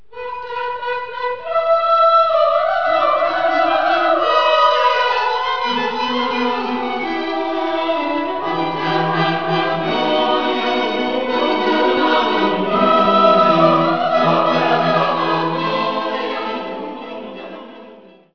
so klingt der Junge Chor